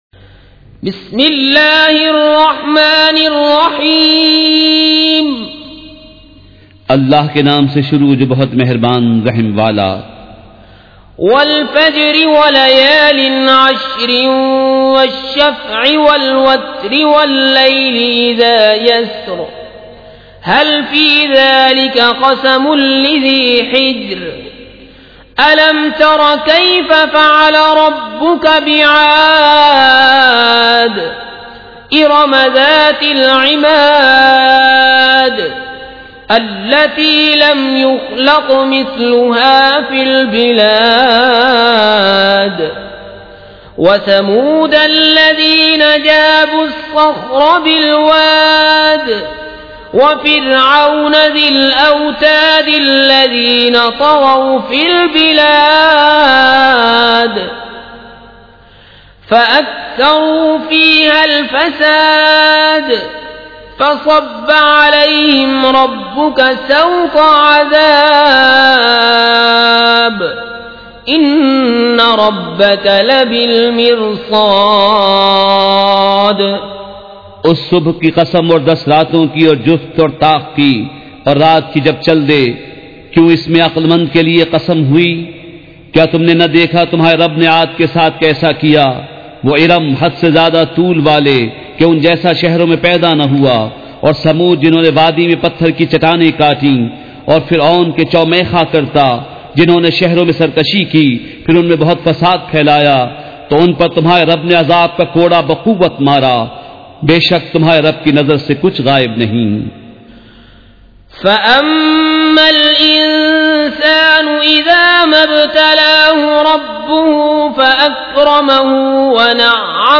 سورۃ الفجر مع ترجمہ کنزالایمان ZiaeTaiba Audio میڈیا کی معلومات نام سورۃ الفجر مع ترجمہ کنزالایمان موضوع تلاوت آواز دیگر زبان عربی کل نتائج 1752 قسم آڈیو ڈاؤن لوڈ MP 3 ڈاؤن لوڈ MP 4 متعلقہ تجویزوآراء